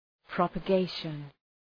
Προφορά
{,prɒpə’geıʃən}